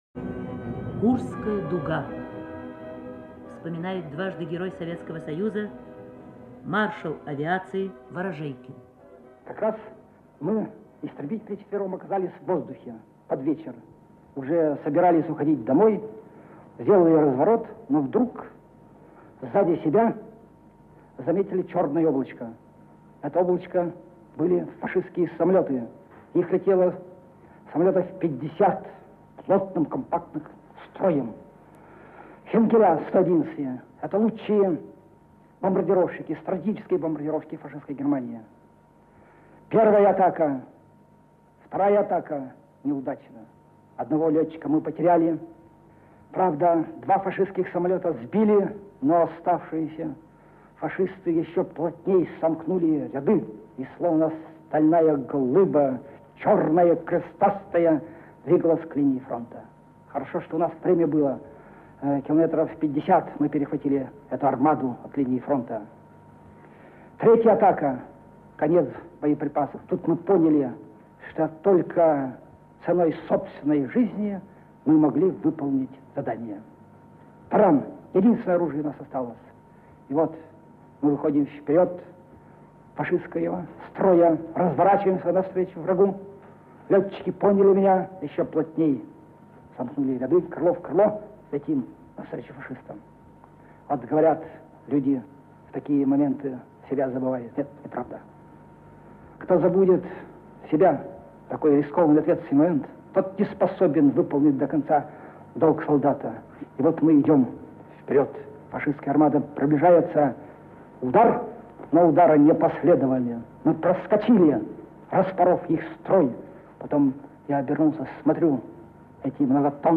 Маршал авиации СССР Григорий Ворожейкин вспоминает об одном из воздушных боев в небе над Курской дугой летом 1943 года (Архивная запись).